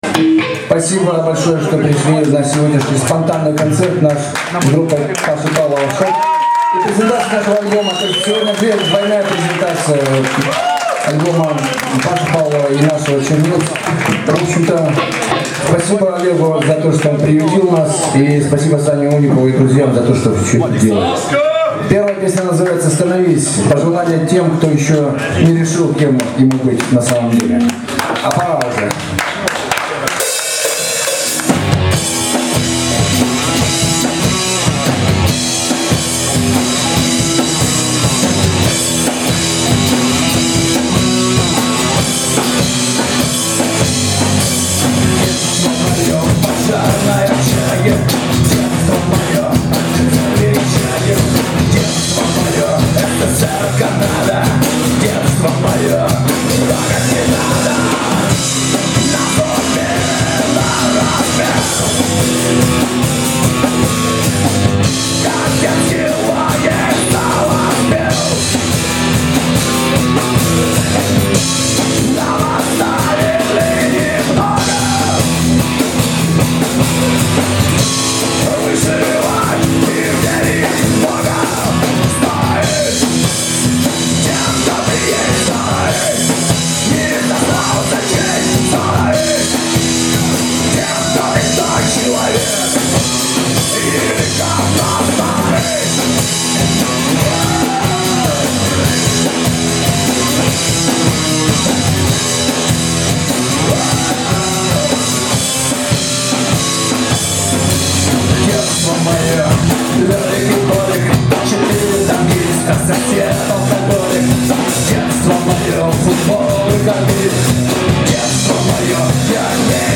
вокал, акустика